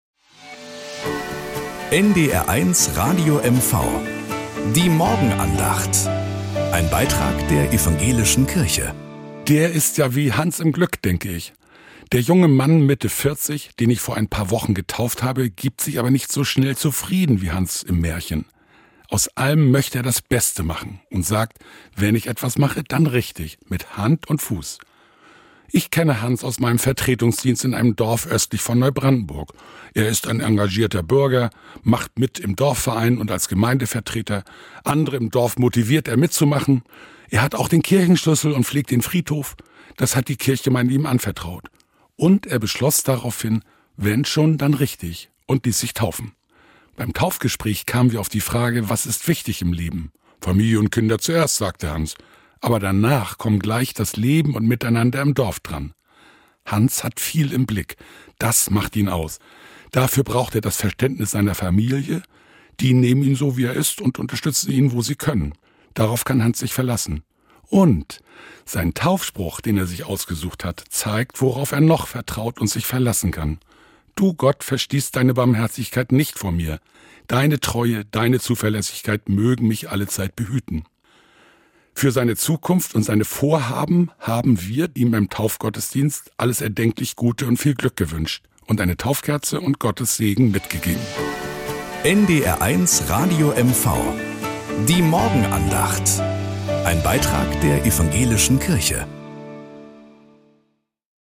Nachrichten aus Mecklenburg-Vorpommern - 26.02.2025